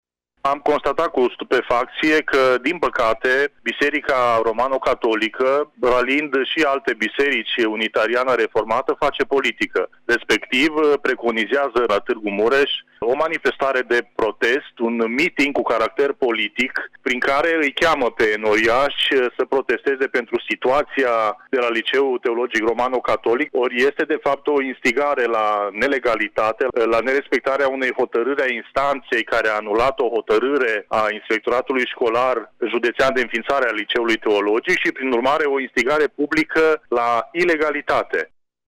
Deputatul Marius Pașcan consideră această mișcare drept o instigare la nelegalitate: